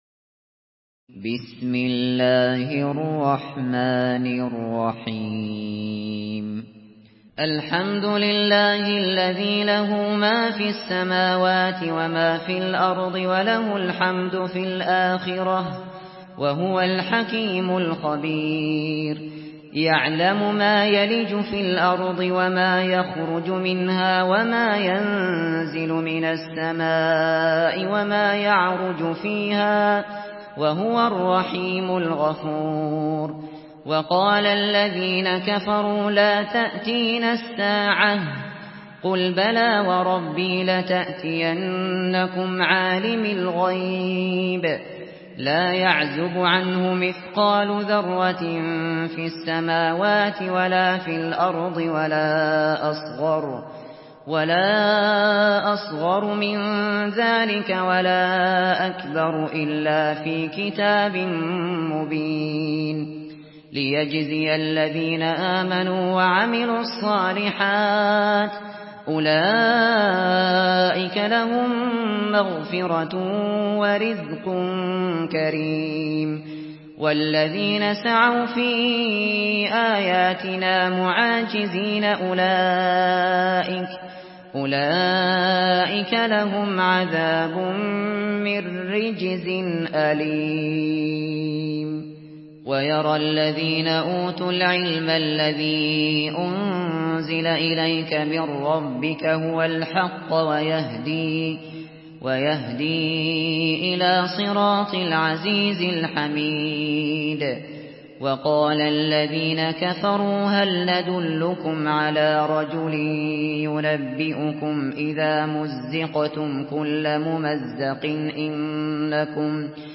سورة سبأ MP3 بصوت أبو بكر الشاطري برواية حفص
مرتل